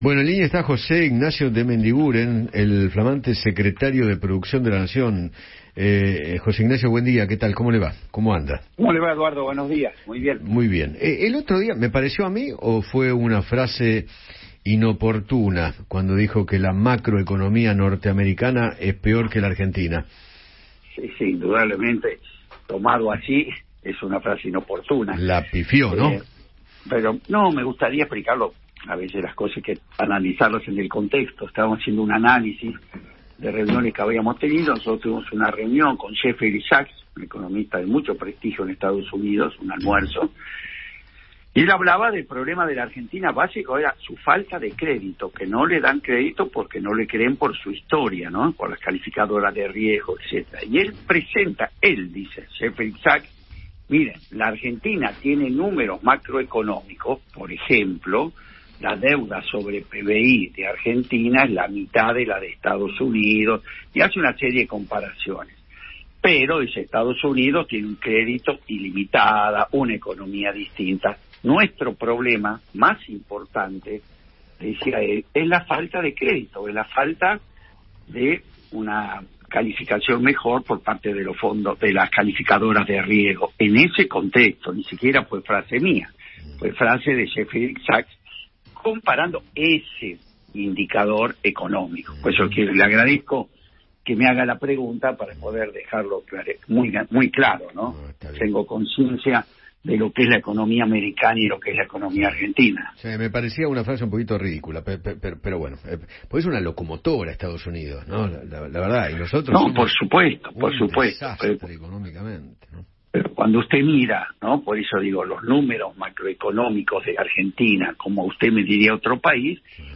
El flamante secretario de Producción, José Ignacio de Mendiguren, conversó con Eduardo Feinmann sobre la coyuntura económica del país y se refirió a la comparación que realizó acerca de la macroeconomía de Argentina y de Estados Unidos.